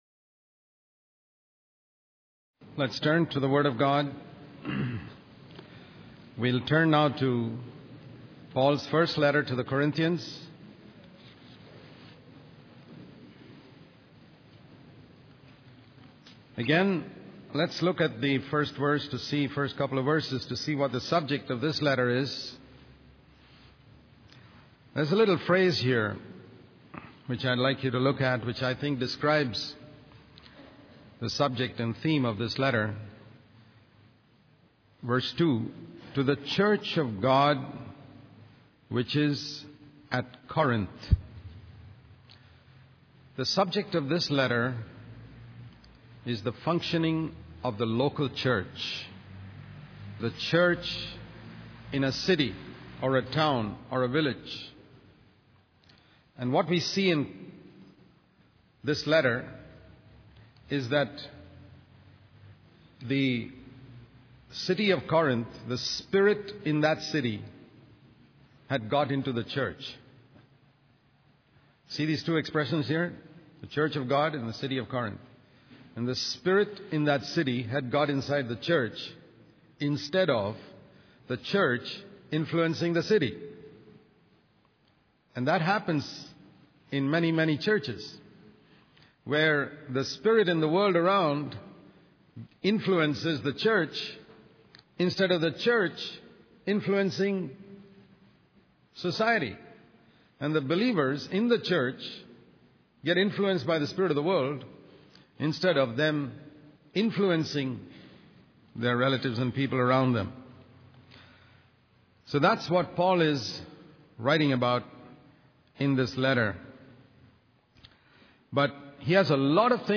In this sermon, the speaker emphasizes the importance of being faithful stewards of Christ.